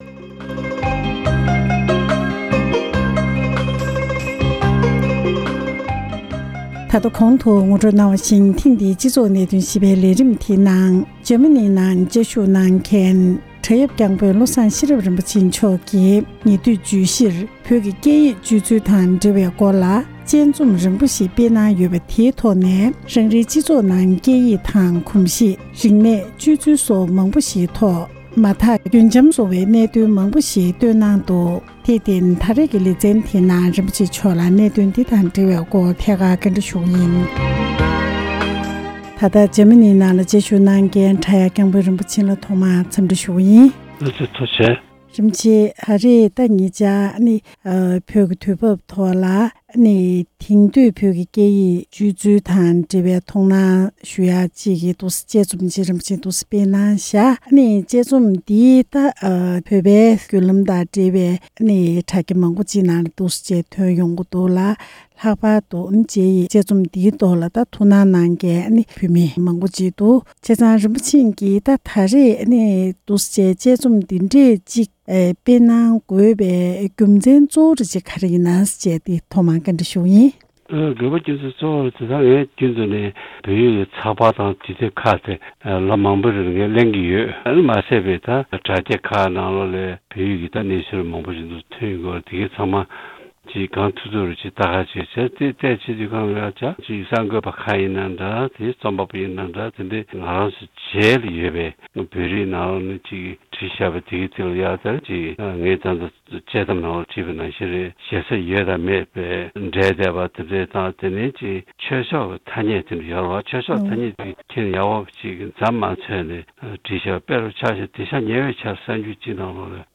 ཞལ་དཔར་བརྒྱུད་ཐད་ཀར་གླེང་མོལ་ཞུས་པ་ཞིག་ལ་གསན་རོགས༎